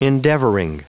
Prononciation du mot endeavoring en anglais (fichier audio)
Prononciation du mot : endeavoring